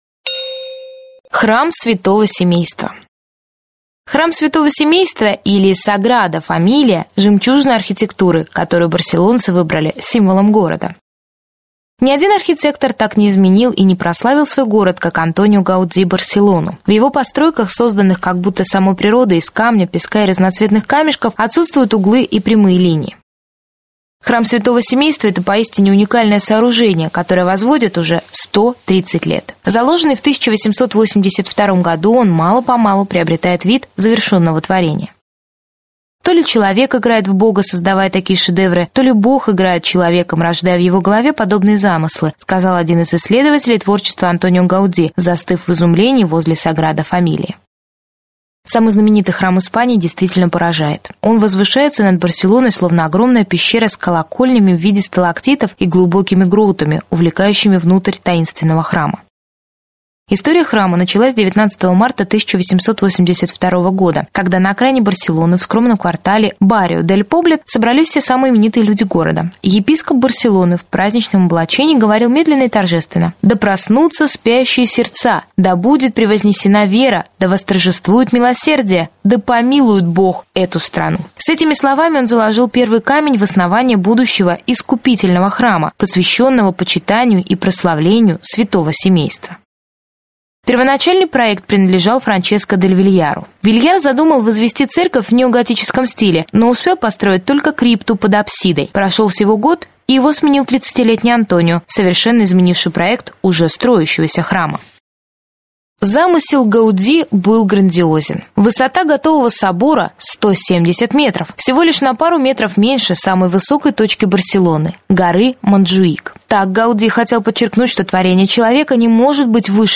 Аудиокнига Барселона: Эшампле и парки. Аудиогид | Библиотека аудиокниг